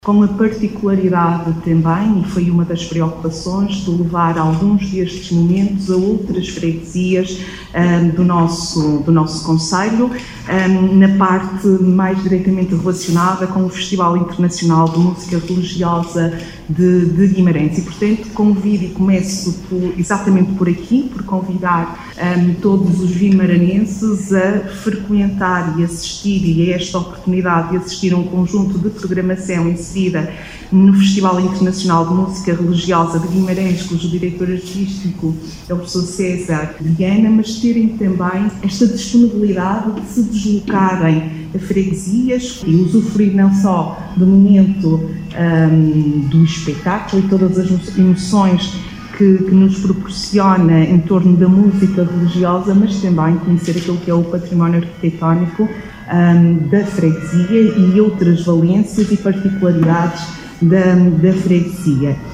Declarações de Isabel Ferreira, vereadora da cultura no Município de Guimarães.